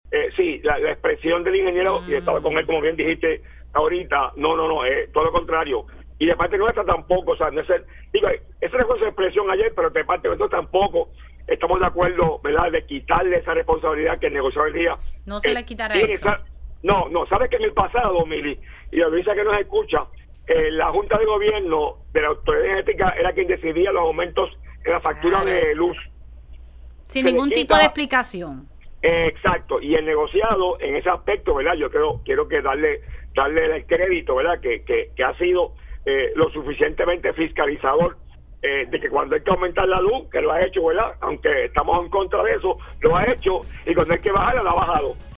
El representante por el Partido Nuevo Progresista, Víctor Parés, aseguró en entrevista con este medio que se malinterpretaron las expresiones del Zar de Energía.